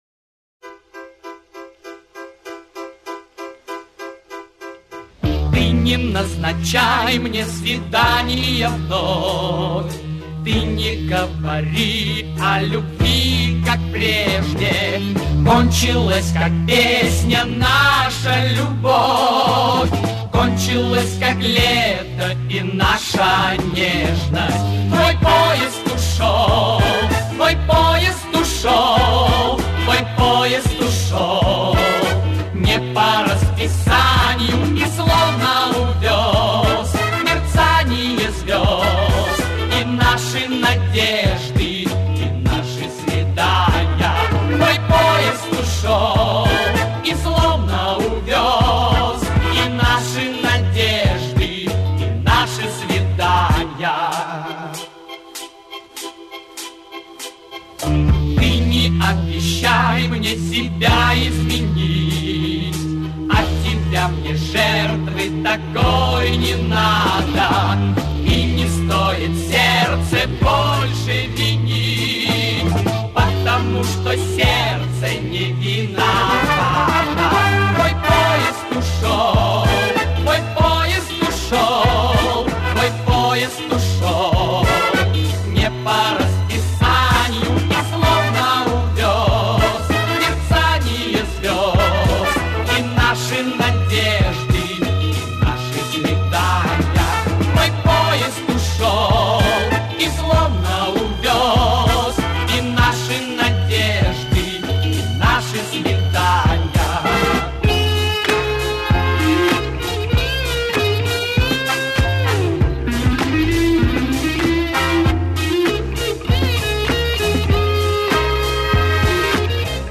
Встали к микрофонам, три-четыре раза пропели и …. свободны.